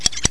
1 channel
snd_27340_Switch sound.wav